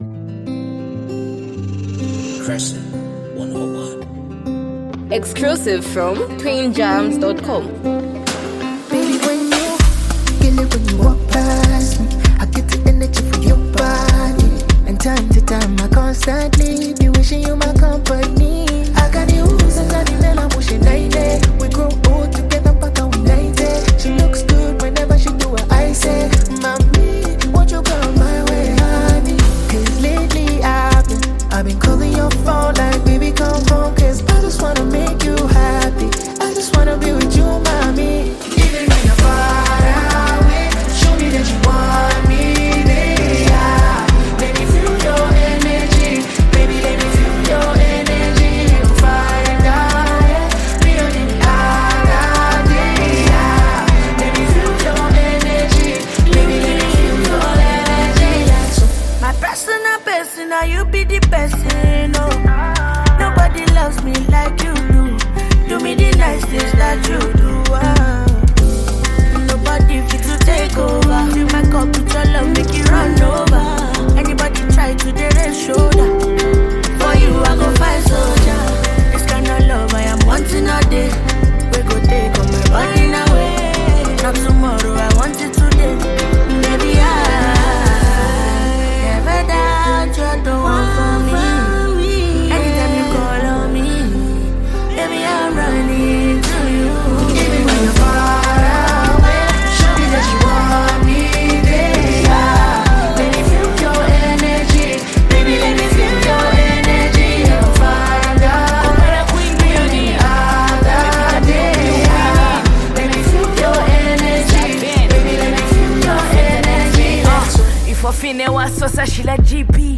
smooth and emotional Afro-fusion track
heartfelt vocals filled with sincerity and vulnerability
rap verse